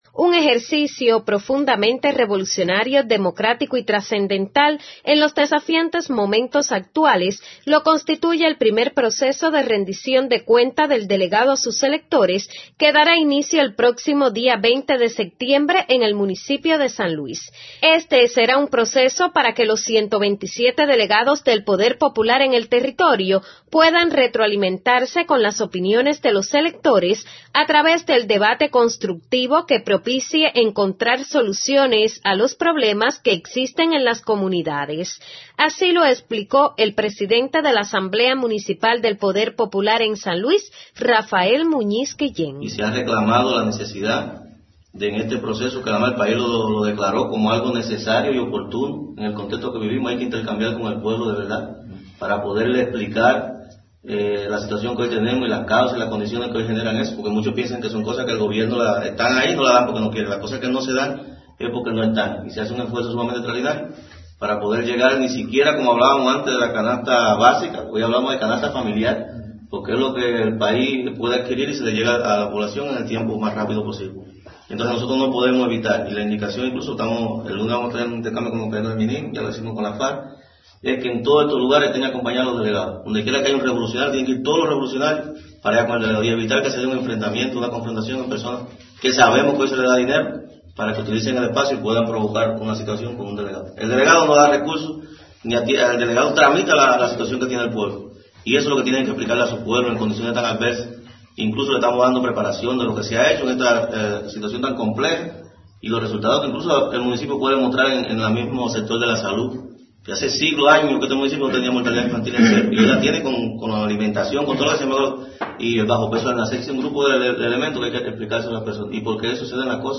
El Presidente de la Asamblea Municipal del Poder Popular aquí, Rafael Muñiz Guillén, expone detalles: